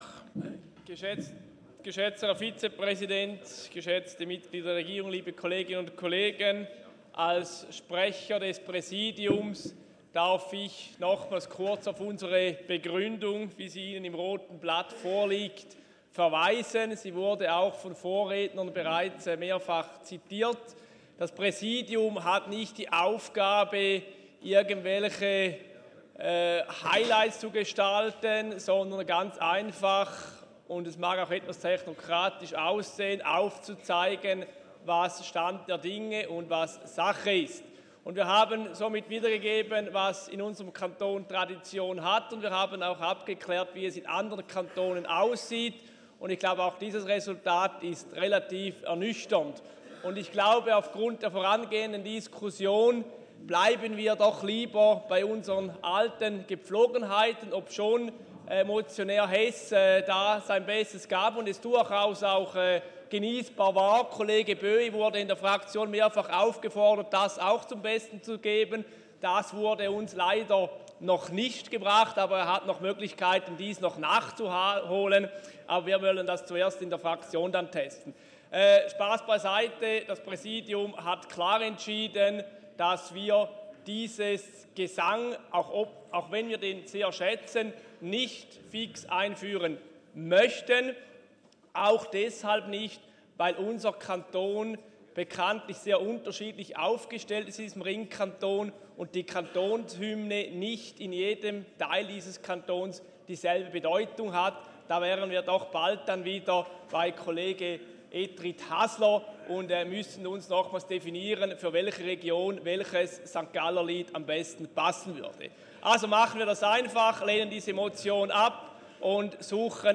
Session des Kantonsrates vom 18. und 19. Februar 2019